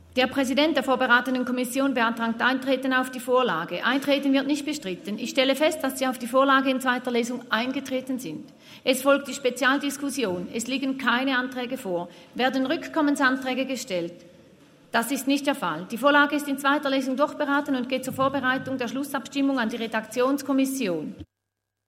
18.9.2023Wortmeldung
Session des Kantonsrates vom 18. bis 20. September 2023, Herbstsession